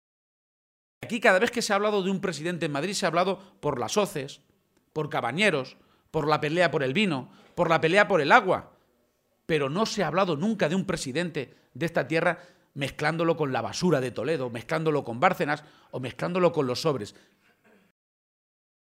Momento del acto público en Montiel